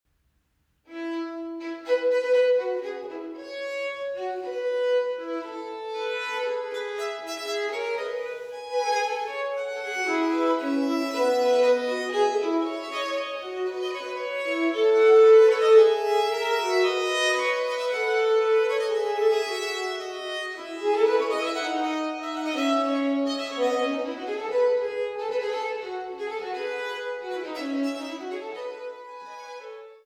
Instrumetalmusik für Hof, Kirche, Oper und Kammer
für drei Soloviolinen